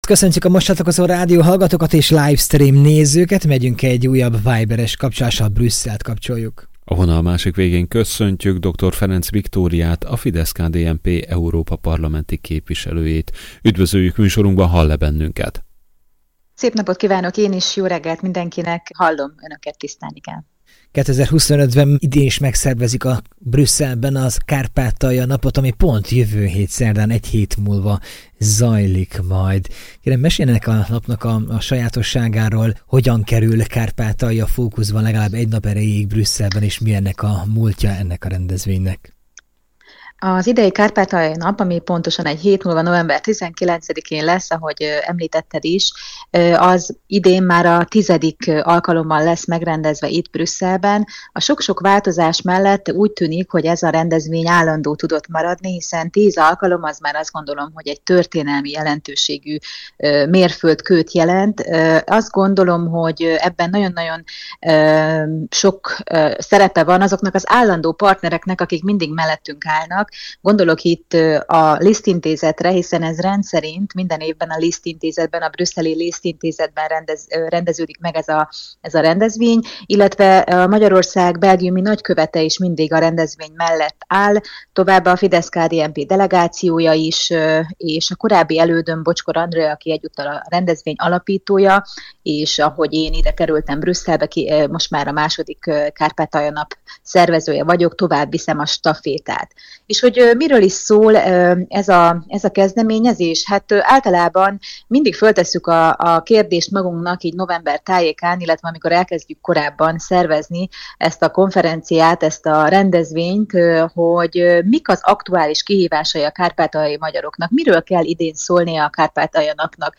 November 19-én szervezik meg a X. Brüsszeli Kárpátalja Napot. Mai Különkiadás c. műsorunkban dr. Ferenc Viktóriával, a FIDESZ-KDNP Európai Parlamenti képviselőjével beszélgettünk: